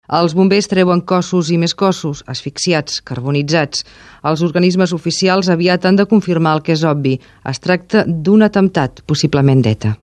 Reportatge: 10 anys de l'atemptat d'Hipercor - Catalunya Ràdio, 1997